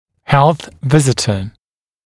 [helθ ‘vɪzɪtə][хэлс ‘визитэ]патронажная сестра, патронажный работник